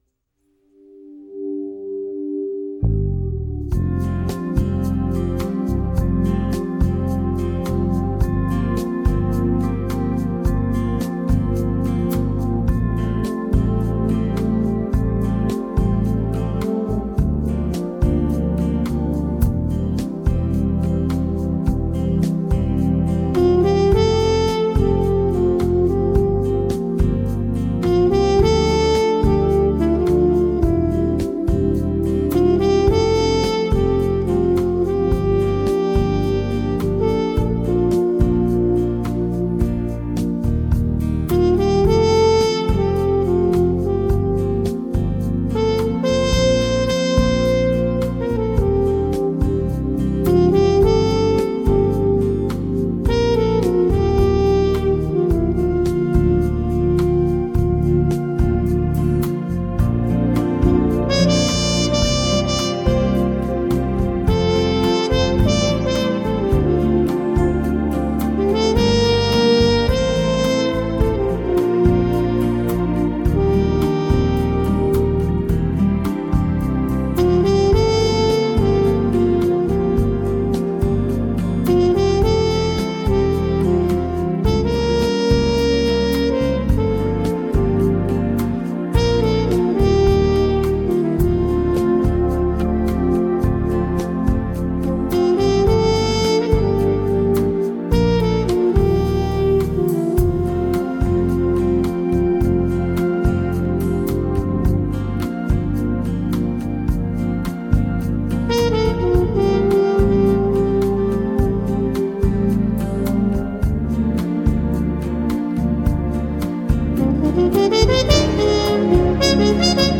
爵士小号手